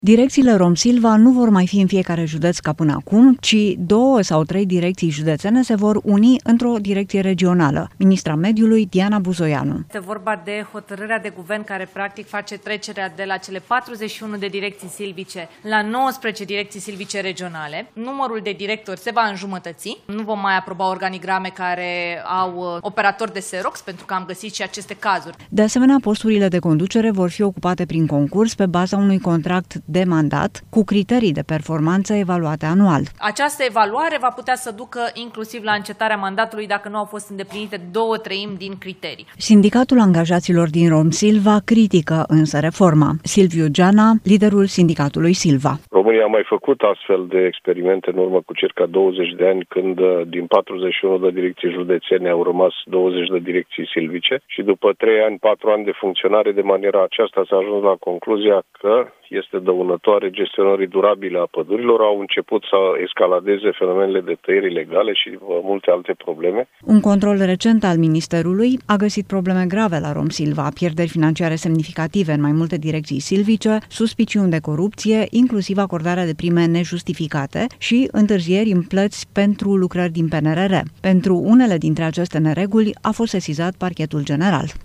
Ministra Mediului, Diana Buzoianu: „Numărul directorilor se va înjumătăți”